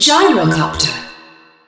gyrocopter.wav